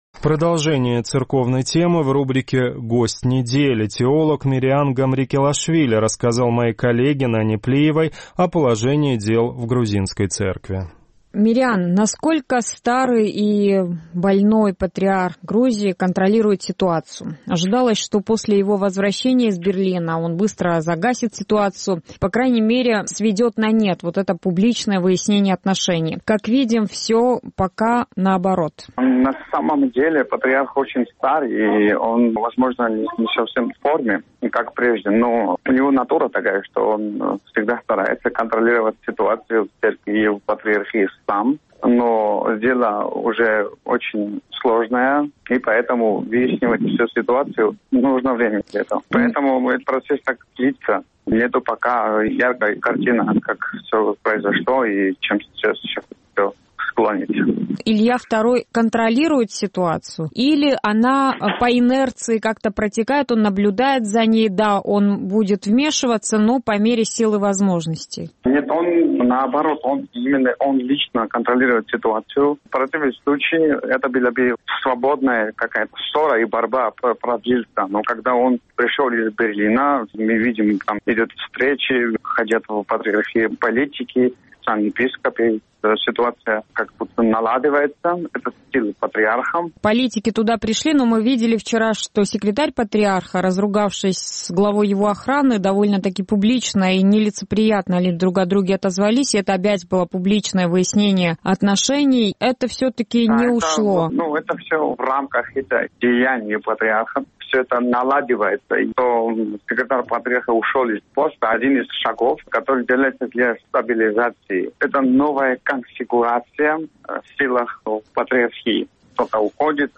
Гость недели